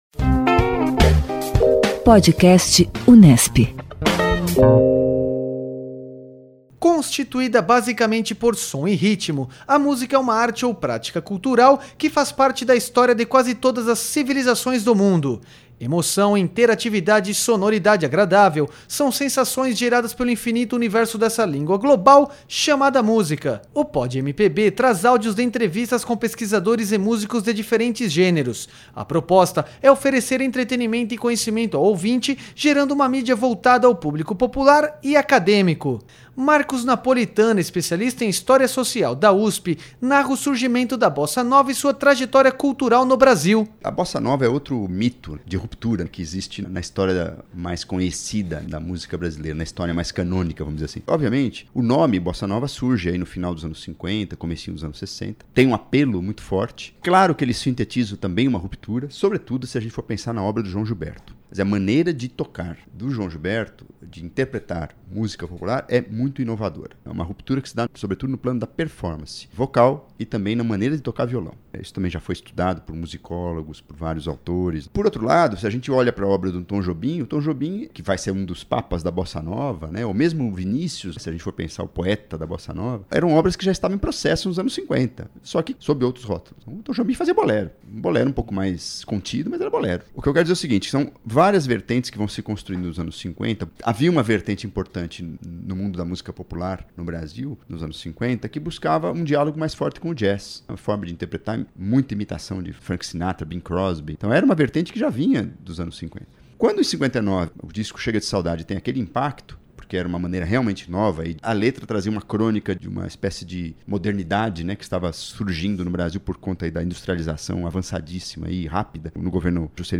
Este PodMPB Unesp conta com a faixa "Desafinado" de Tom Jobim e Newton Mendonça.